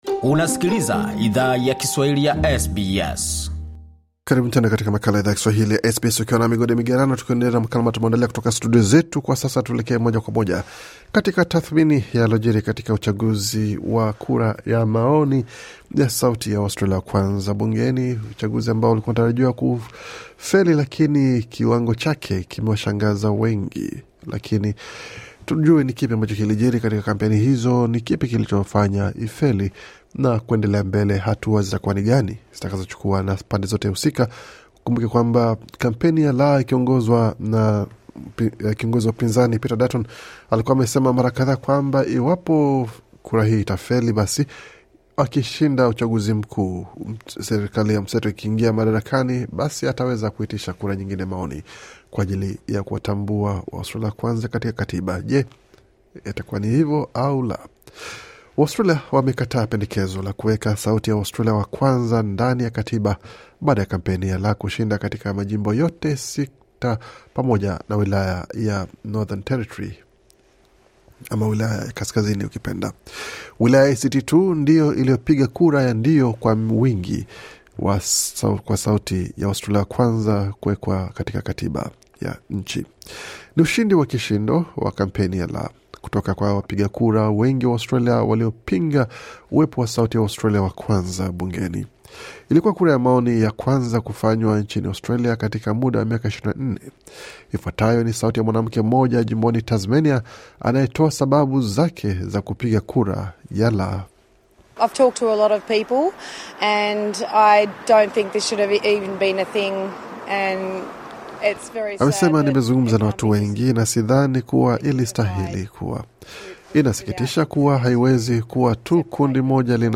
Ifuatayo ni sauti ya mwanamke mmoja jimboni Tasmania anaye toa sababu zake zakupiga kura ya La.